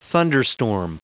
Prononciation du mot thunderstorm en anglais (fichier audio)
Prononciation du mot : thunderstorm